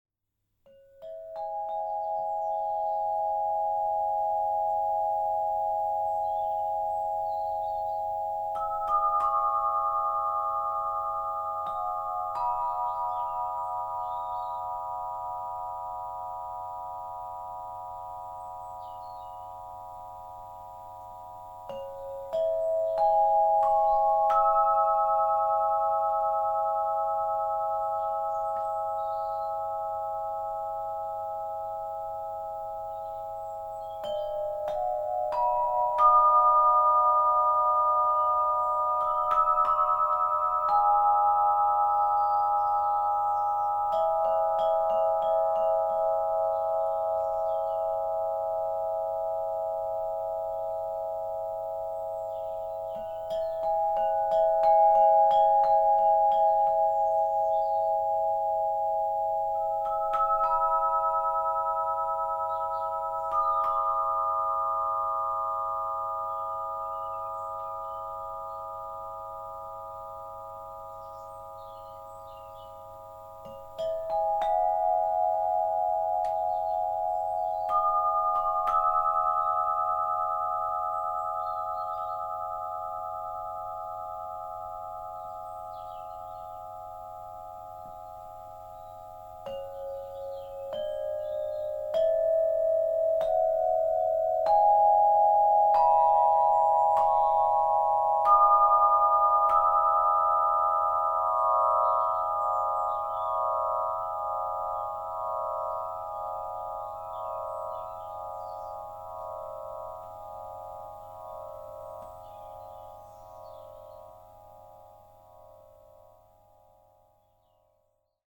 Tubalophone • Eau
Celui-ci est composé de 9 tubes et il est basé sur l’élément de l’eau pour produire des sonorités fluides et apaisantes.
Grâce à son jeu intuitif, le tubalophone offre une expérience immersive où chaque note résonne avec pureté et profondeur.
• Inspiré de l’élément Eau, avec un son fluide et harmonieux
• Harmoniques riches et vibrantes, idéales pour la relaxation et la sonothérapie
• Vendu avec mailloche •